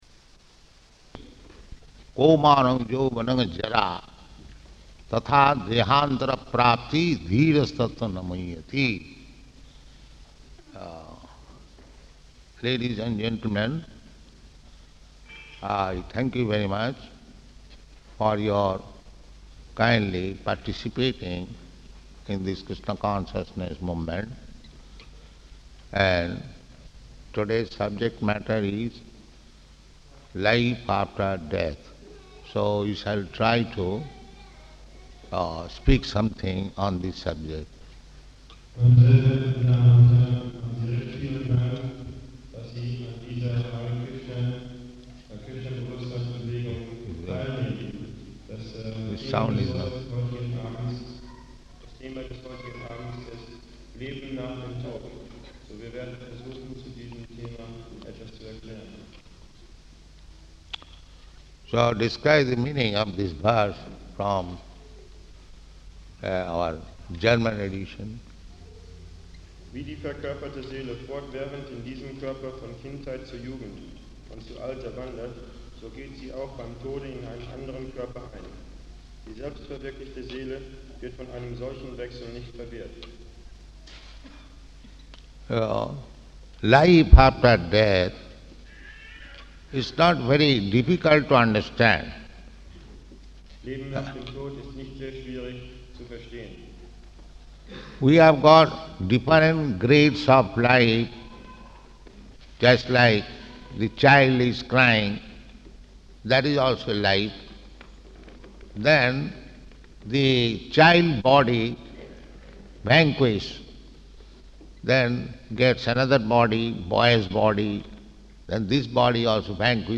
Location: Bad Homburg, Germany
[translated into German throughout]
[German translator reads German translation] So life after death is not very difficult to understand.